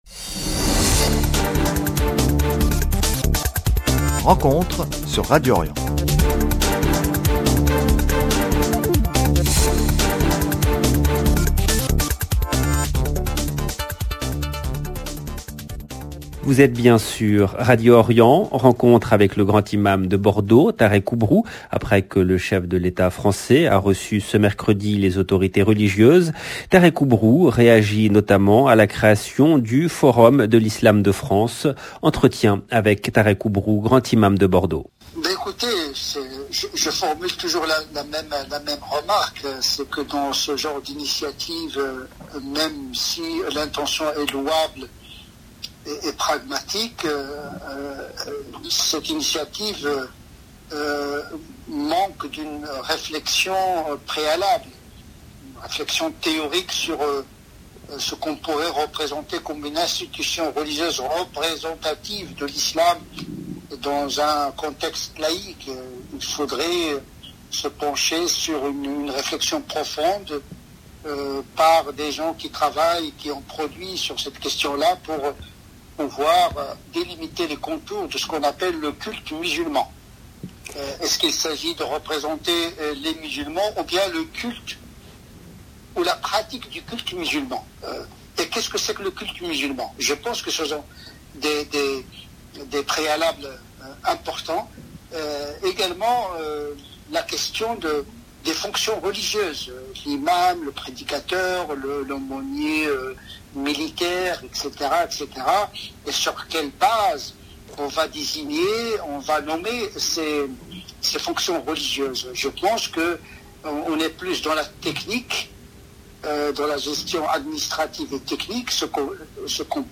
Emission